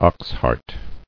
[ox·heart]